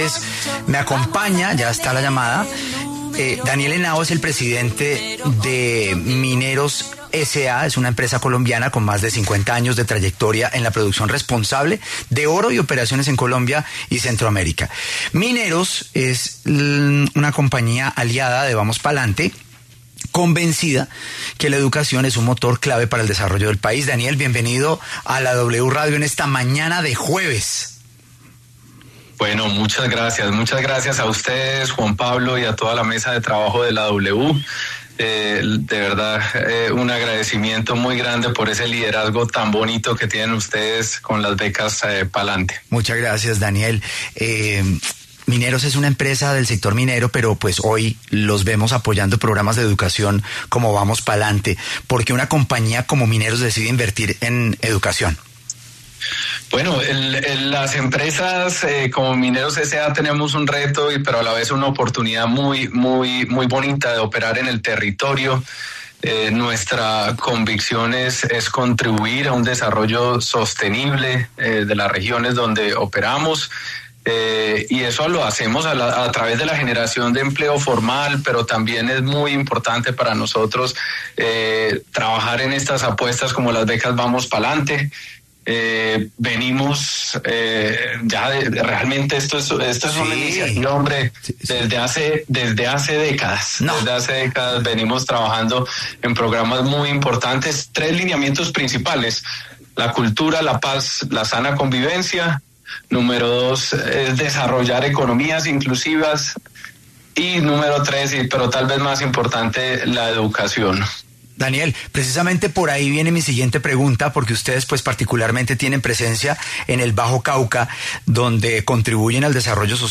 pasó por los micrófonos de La W y anunció que la empresa se unirá a la octava edición de la campaña Vamos Pa’ Lante que busca otorgar becas de acceso